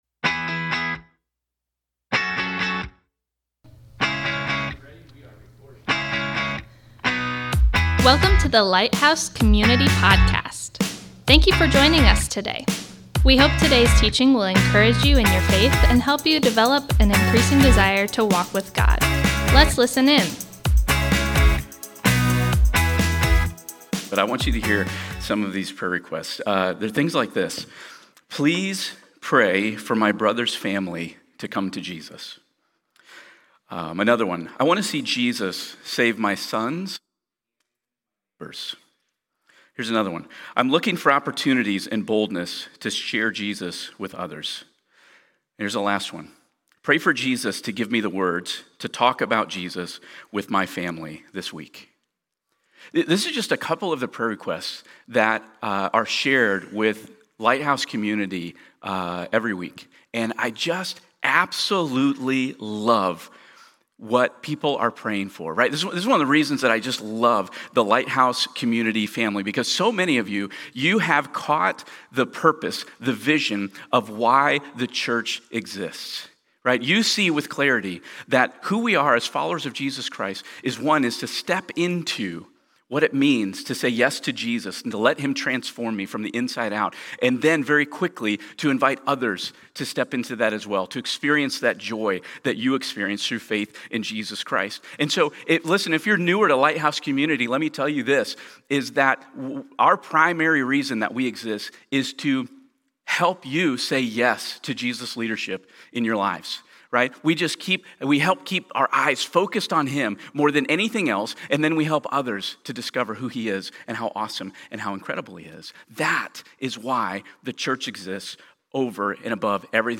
Thank you for joining us today as we come together to worship! This morning, we’re turning to John 20:31, where Scripture shows us Jesus’ true identity and calls us to believe in Him. We’ll be unpacking who He is and what it means to trust Him with genuine, saving faith which brings new life, forgiveness, and a restored relationship with God through Christ.